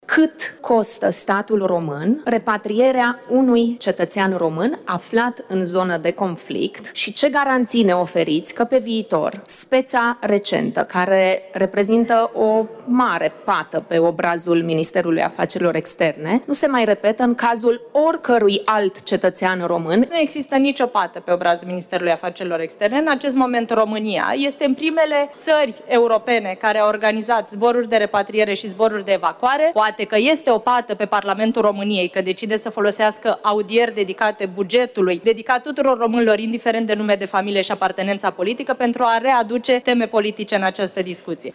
Ministra de Externe, chestionată de o deputată AUR în legătură cu repatrierea românilor din Orientul Mijlociu.
Deputata AUR, Ramona Ioana Bruynseels, i-a reproșat ministrei de Externe modul în care s-a făcut repatrierea românilor din Orientul Mijlociu
18mar-11-Bruynseels-si-replica-Oanei-Toiu.mp3